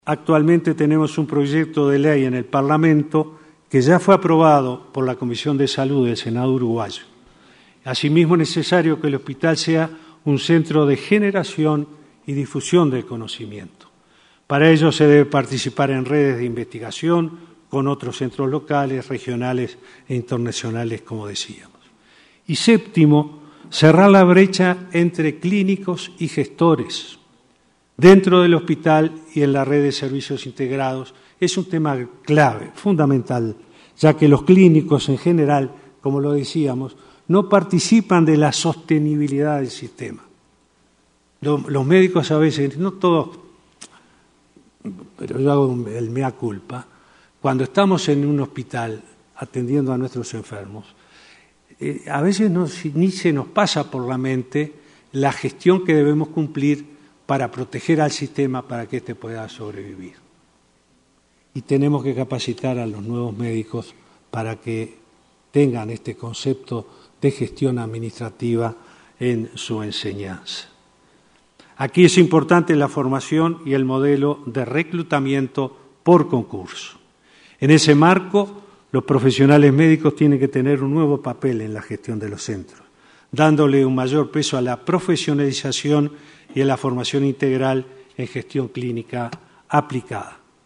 El presidente Tabaré Vázquez destacó la necesidad de mejorar la gestión integral de la salud capacitando a los médicos sobre la sostenibilidad de sistema sanitario, sin dejar de priorizar la salud del paciente y el uso responsable de recursos. En su discurso en el XVI Congreso Internacional Hospital del Futuro, hacia Nuevos Modelos de Salud, afirmó que la mayor eficiencia y eficacia de la gestión de salud es un tema de todos.